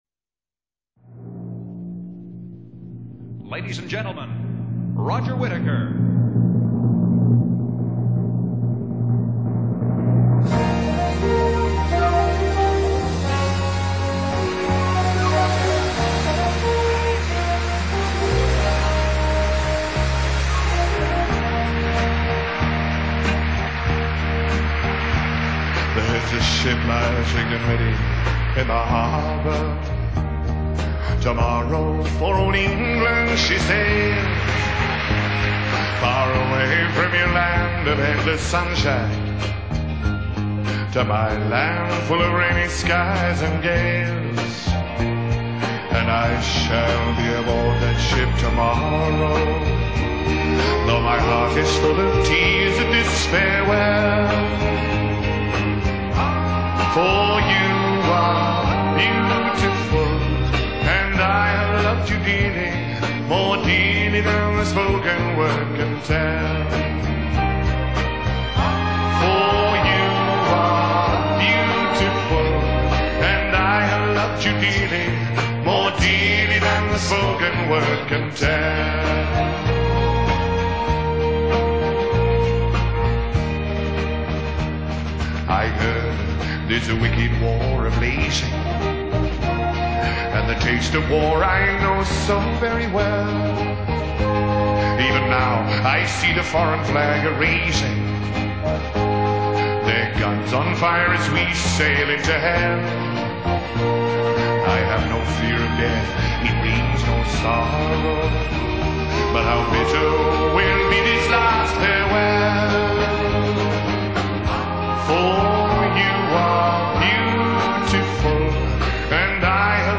Genre: Country, folk, easy listening